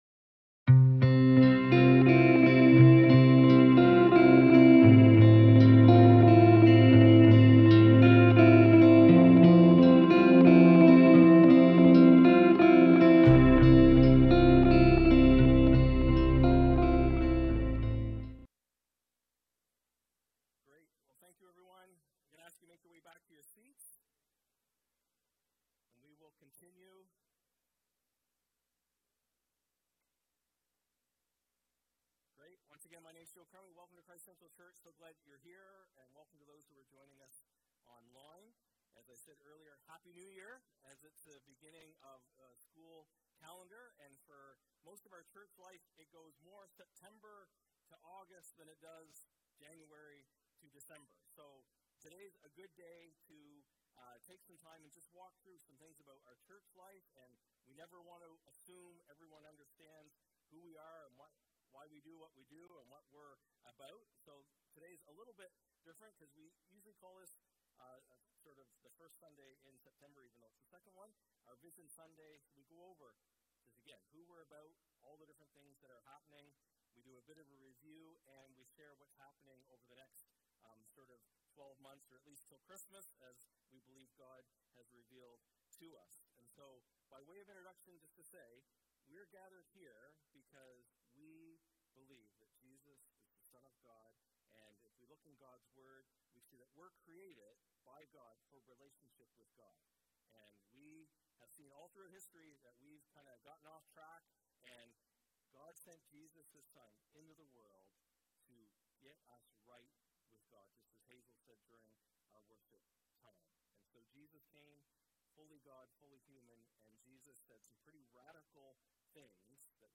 Sermons | Christ Central Church